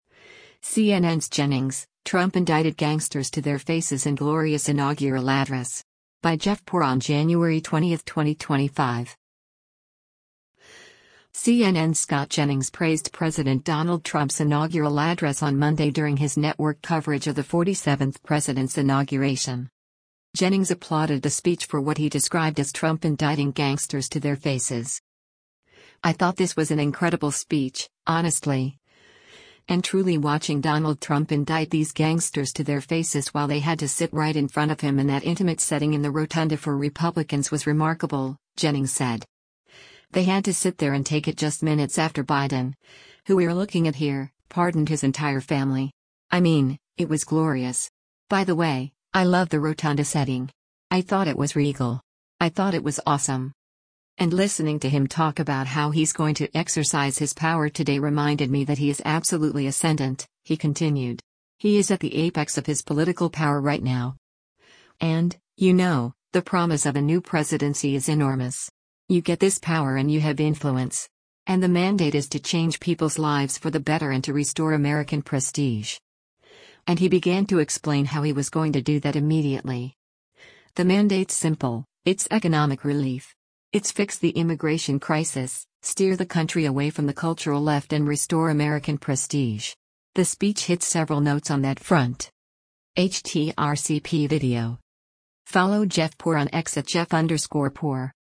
CNN’s Scott Jennings praised President Donald Trump’s inaugural address on Monday during his network coverage of the 47th president’s inauguration.